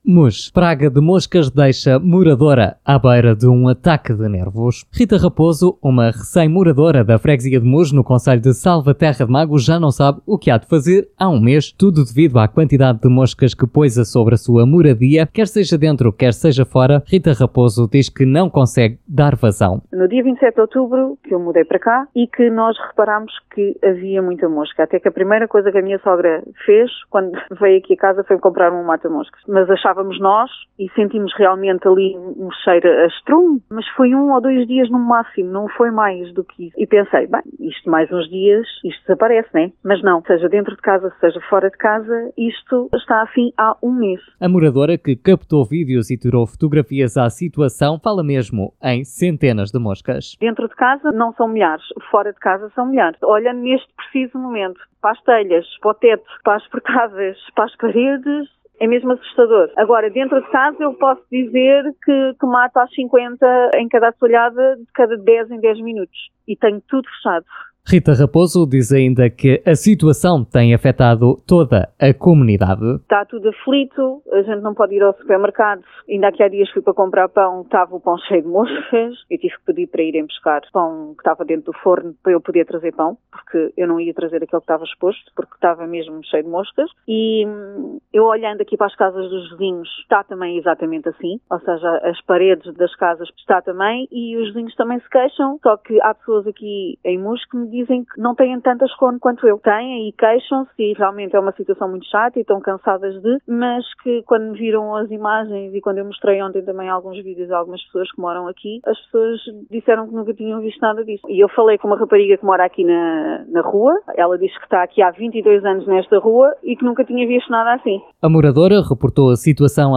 Em viva voz, aos microfones da Rádio Marinhais, relata que a situação começou precisamente há um mês.